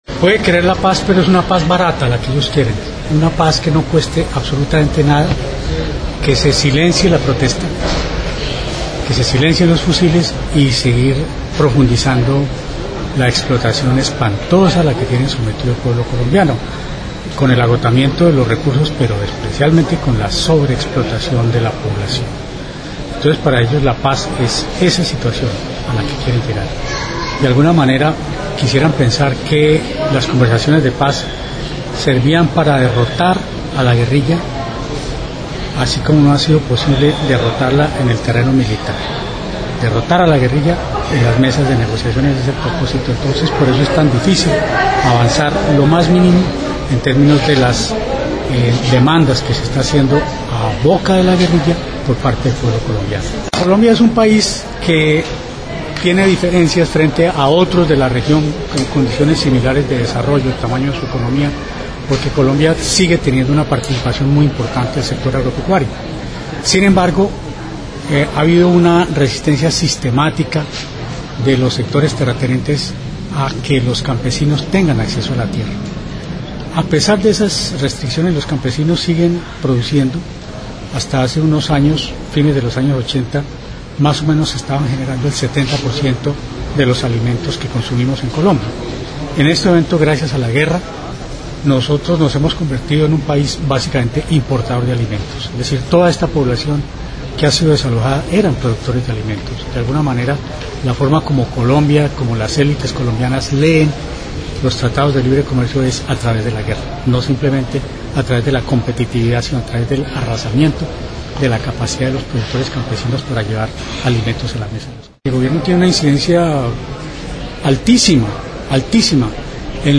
en el marco del II Foro por la Paz de Colombia realizado en Montevideo.